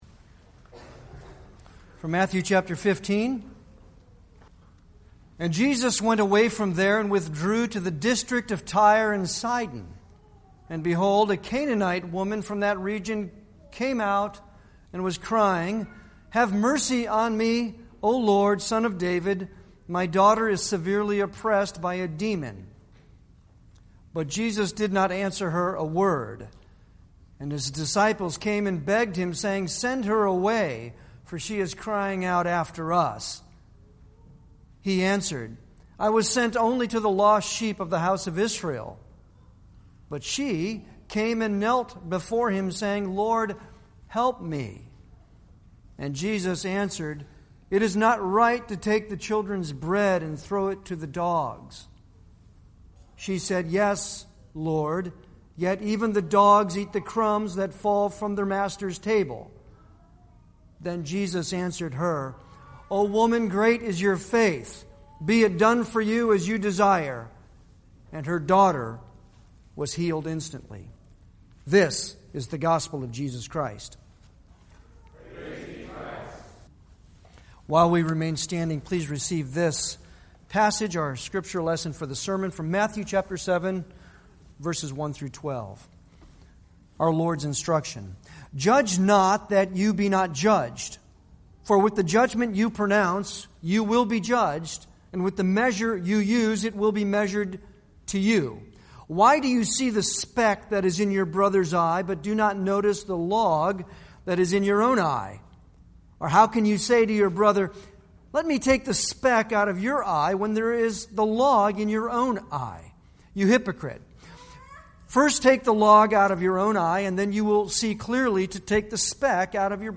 A sermon
Service Type: Sunday worship